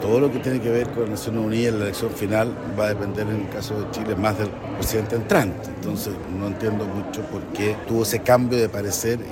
El senador de la Unión Demócrata Independiente (UDI), Juan Antonio Coloma, planteó que el Ejecutivo debiera permitir que el presidente electo sea quien tome la conducción y defina el futuro de la candidatura de Bachelet.